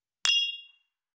271,バー,お洒落,モダン,カクテルグラス,ショットグラス,おちょこ,テキーラ,シャンパングラス,カチン,チン,カン,ゴクゴク,プハー,シュワシュワ,コポコポ,ドボドボ,トクトク,カラカラ,
コップ